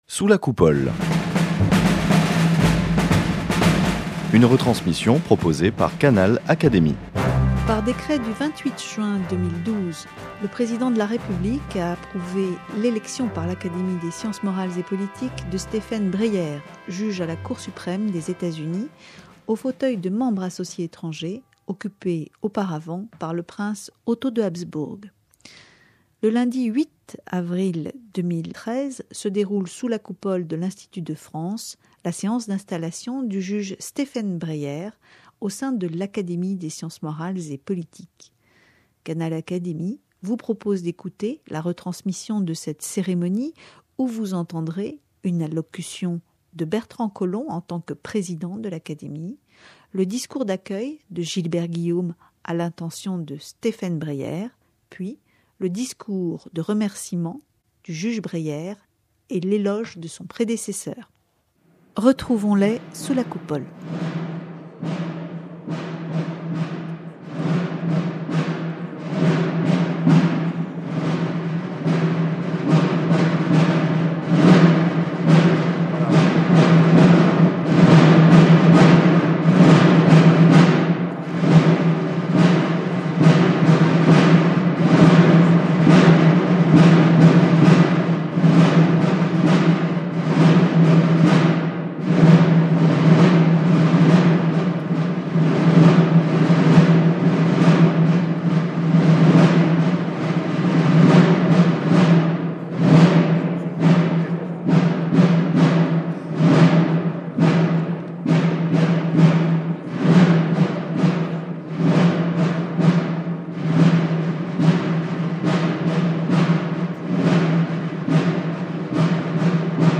Le juge à la Cour suprême américaine Stephen Breyer est reçu sous la Coupole au sein de l’Académie des sciences morales et politiques
Le juge Breyer défend un droit humaniste et ouvert dans le cadre des évolutions complexes des droits nationaux et du droit international et de leurs imbrications. Canal Académie vous propose d’écouter l’intégralité de cette séance, dans laquelle le juge Breyer rend un vibrant hommage à Otto de Habsbourg.